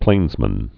(plānzmən)